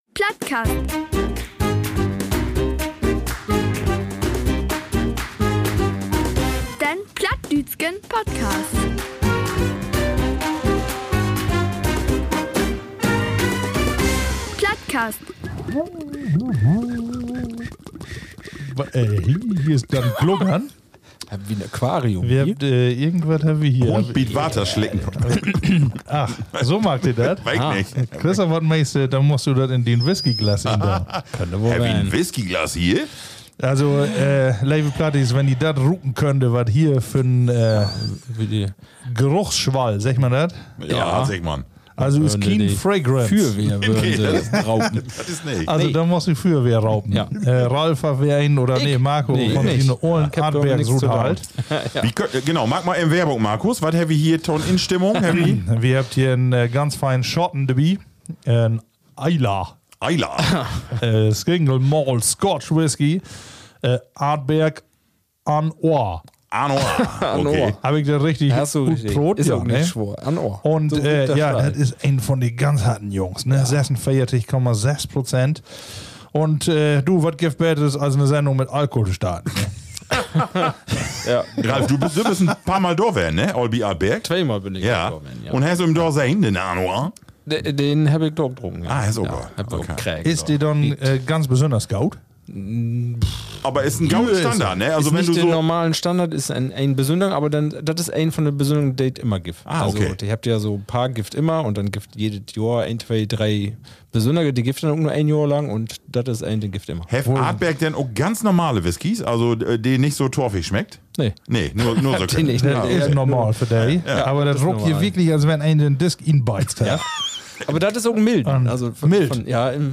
Beschreibung vor 2 Wochen  **Plattcast #101 – Zurück in ruhigeres Fahrwasser** Nach unserer großen Jubiläumsfolge geht es im **Plattcast** wieder etwas gemütlicher zu. Folge **101** bringt uns zurück in vertraute Gewässer: entspanntes Schnacken, plattdeutsche Geschichten und die gewohnt lockere Atmosphäre – genau so, wie ihr unseren Podcast kennt und mögt.
Zwei besondere **Hopfenkaltschorlen** sorgen für gute Stimmung am Mikrofon: *Der blaue Page* aus der herzoglichen Brauerei Tegernsee sowie das traditionsreiche *Mahrs Bräu „U“* aus Bamberg.